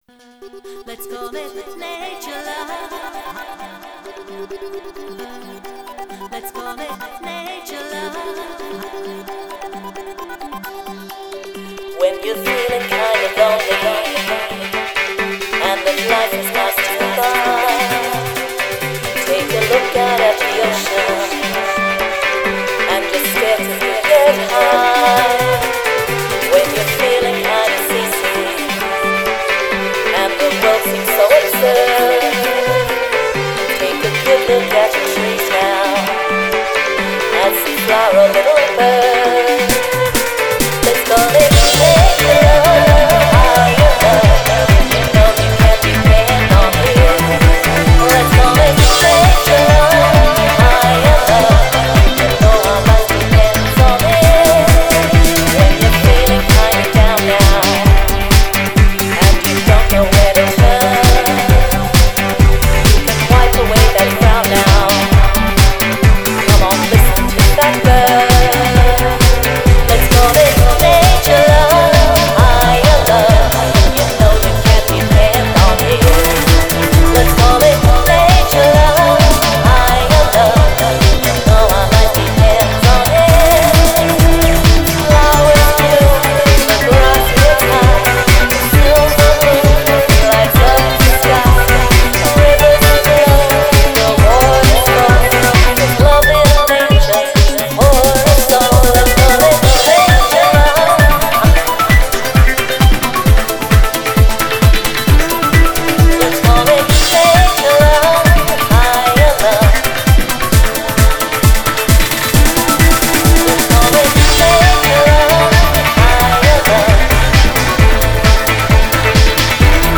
solo dance CD